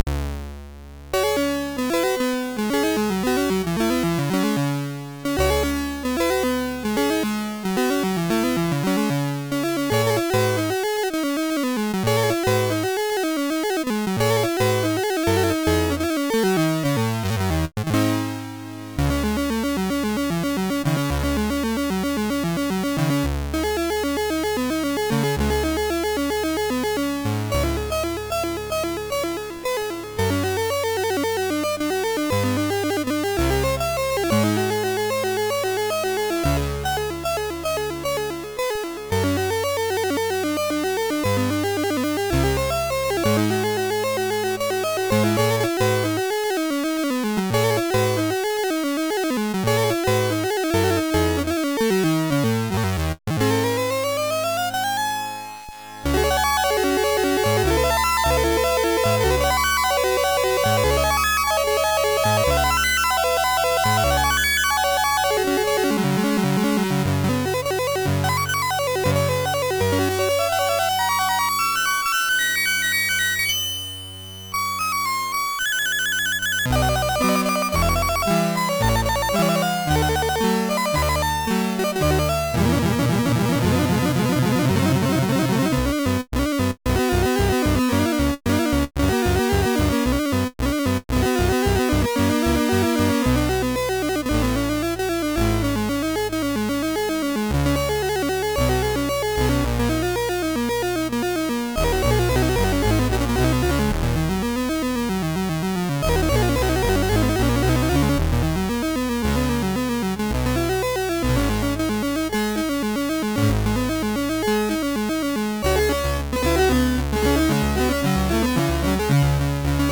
Commodore SID Music File